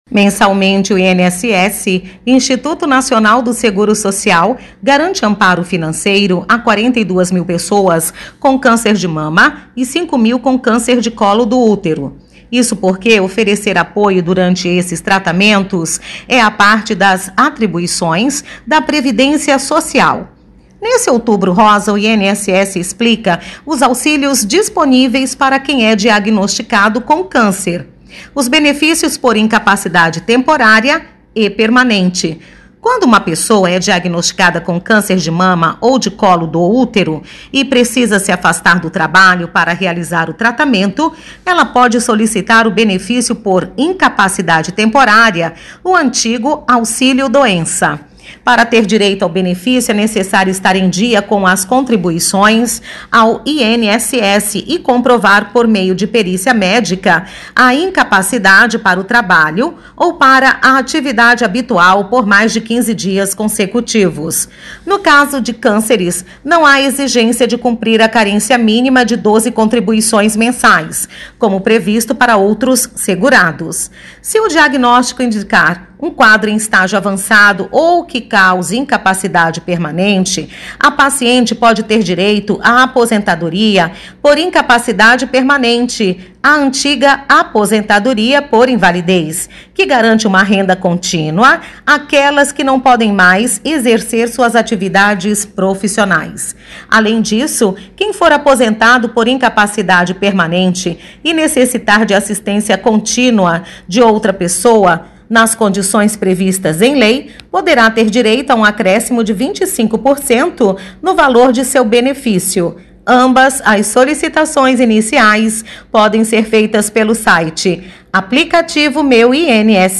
Boletins de MT 14 out, 2025